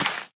Minecraft Version Minecraft Version latest Latest Release | Latest Snapshot latest / assets / minecraft / sounds / block / sweet_berry_bush / place4.ogg Compare With Compare With Latest Release | Latest Snapshot